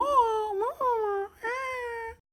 talking2.mp3